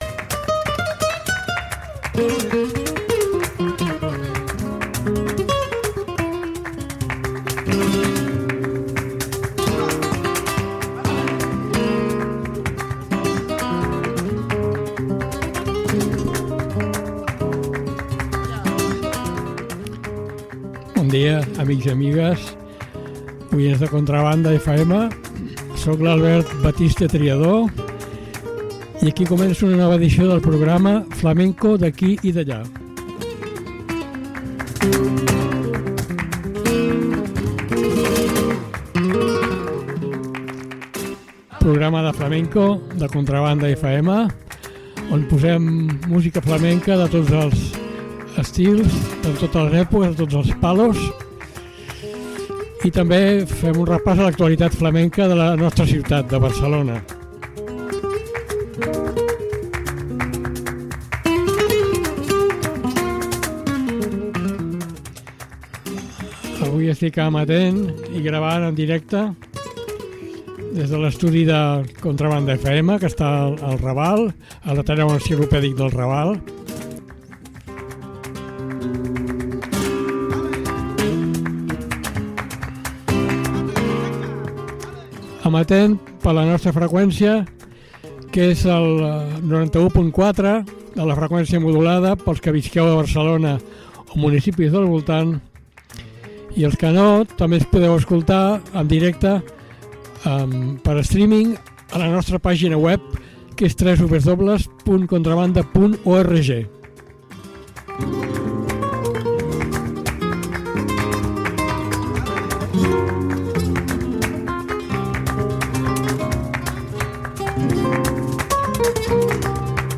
BULERÍAS – 08/02/2025